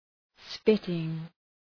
Shkrimi fonetik {‘spıtıŋ}